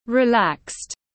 Thư giãn tiếng anh gọi là relaxed, phiên âm tiếng anh đọc là /rɪˈlækst/
Relaxed /rɪˈlækst/